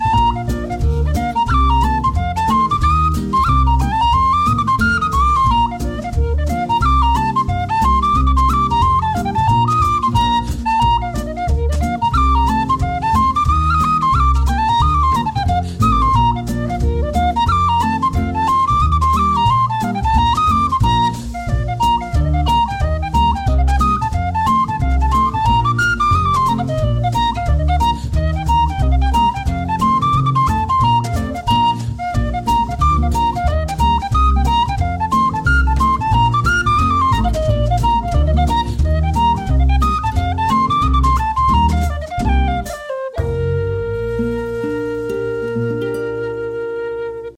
traditional Irish music